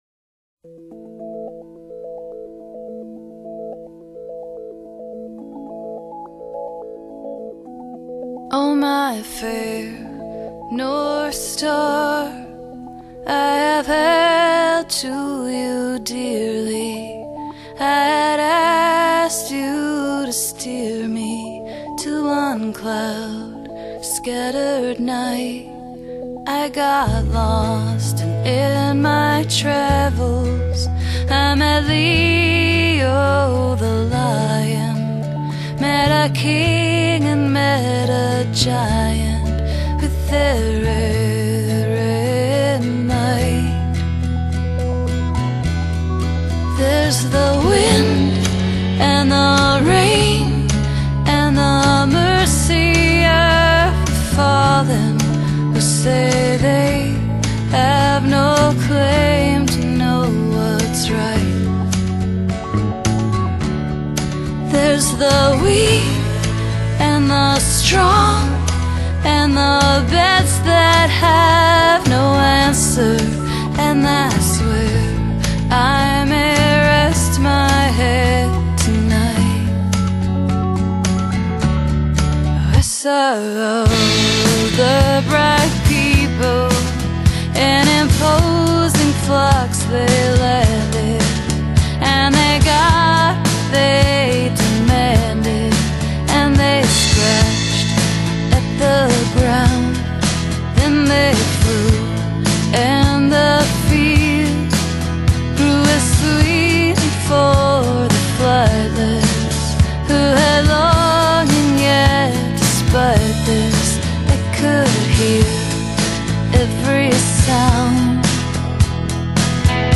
【清新的民謠歌手】